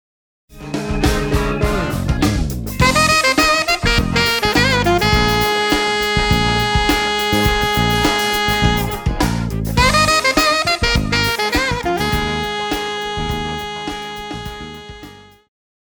爵士
旋律和絃譜,電吉他,中音薩克斯風
樂團
演奏曲
時尚爵士,現代爵士,放克,融合爵士
獨奏與伴奏,五重奏
有節拍器